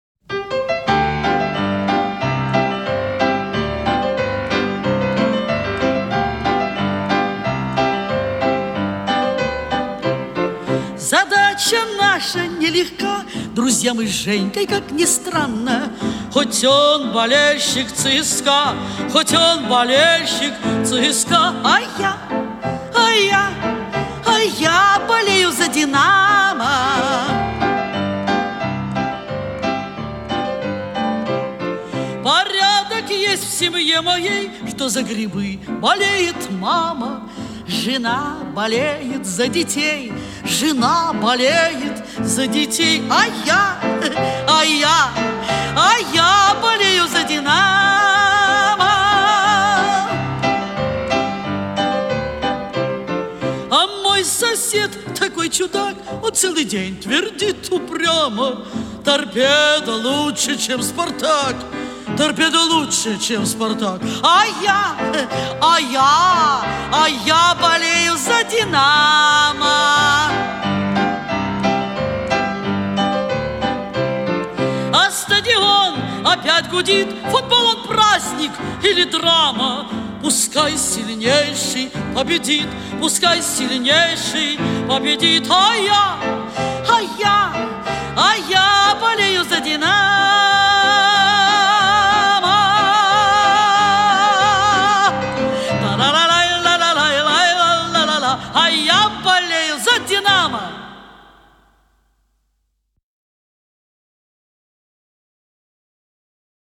И слово уже разборчиво.